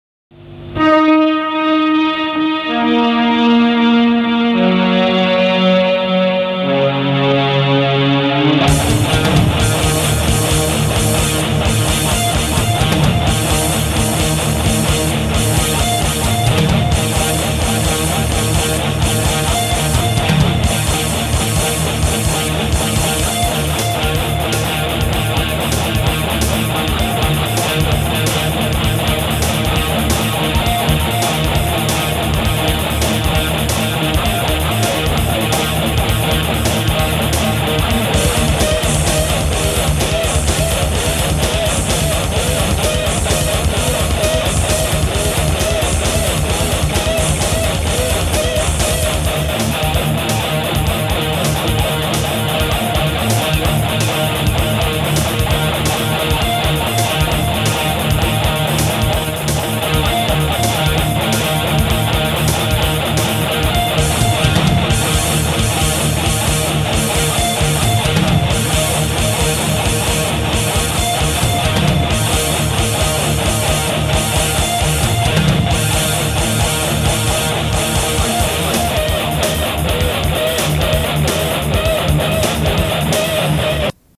Kapitalna perkusja!